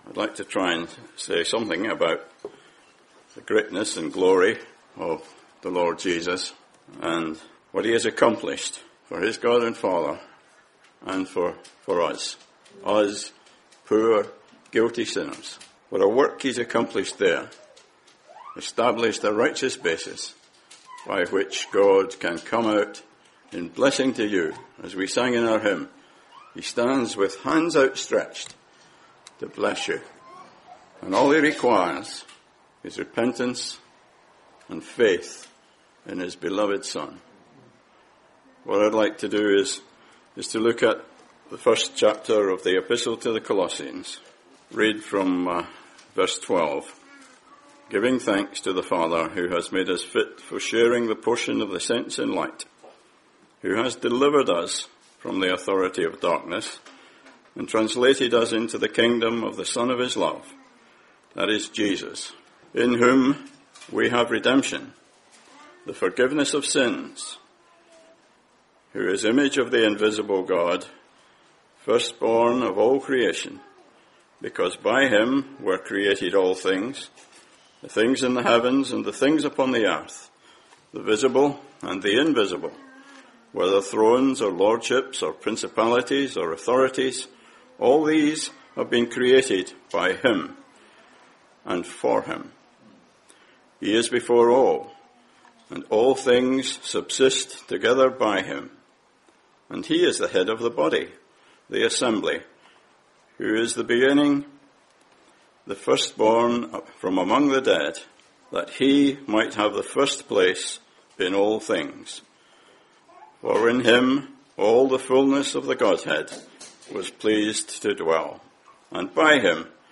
Listen to this Gospel preaching, and learn what God has done through the Lord Jesus to make us fit to enter his holy presence and receive salvation.